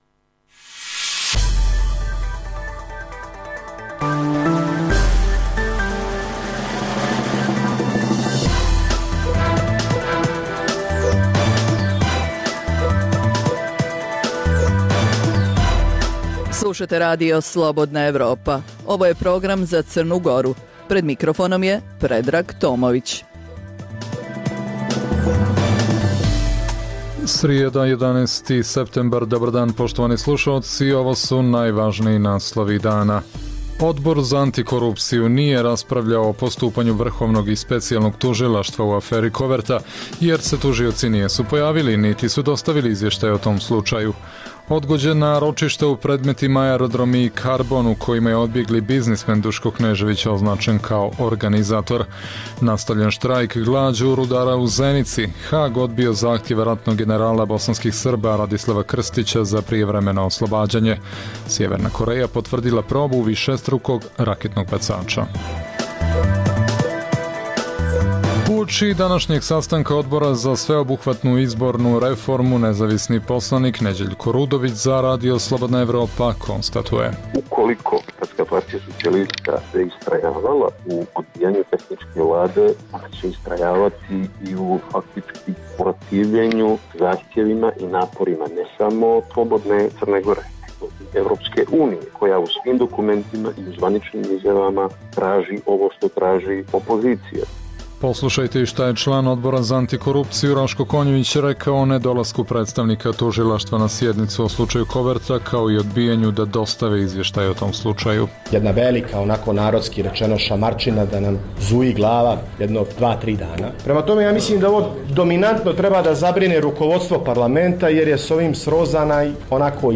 Emisija namijenjena slušaocima u Crnoj Gori. Sadrži lokalne, regionalne i vijesti iz svijeta, tematske priloge o aktuelnim dešavanjima iz oblasti politike, ekonomije i slično, te priče iz svakodnevnog života ljudi, kao i priloge iz svijeta.